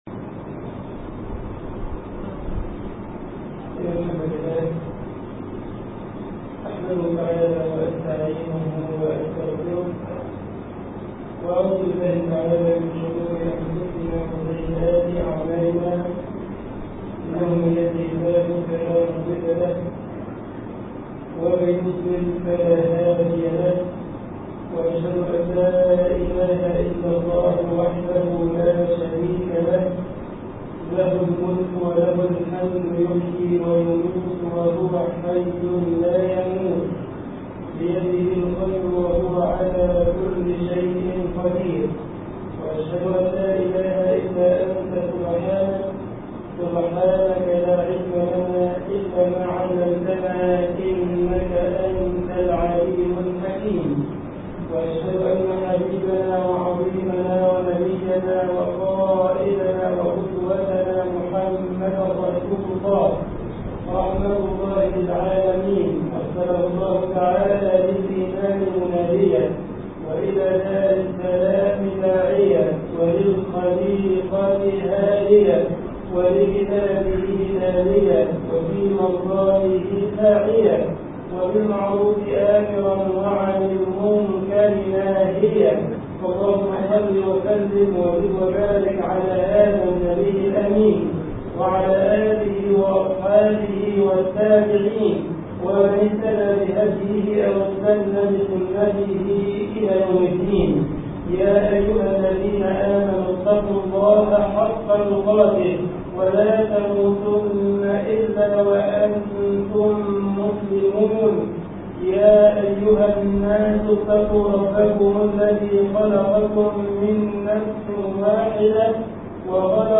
خطبة الجمعة
مسجد المنوفي غمرة ـ الشرابية ـ القاهرة